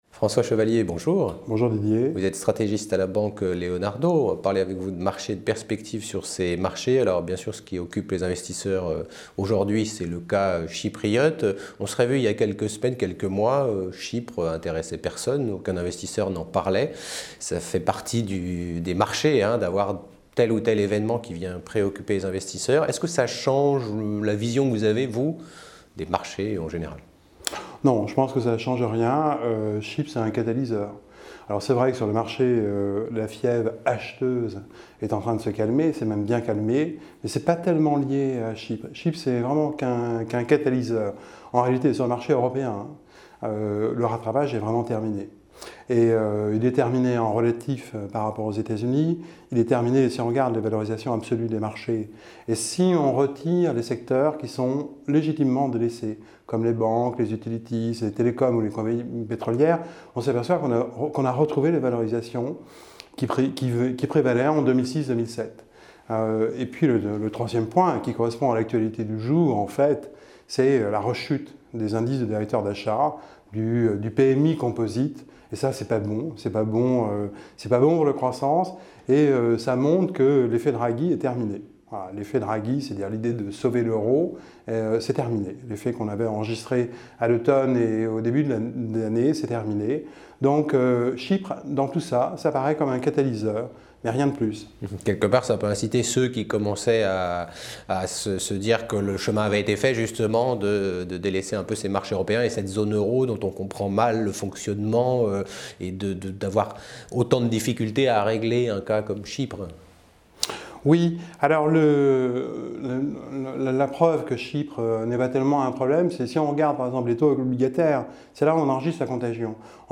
Bourse - Crise : Interview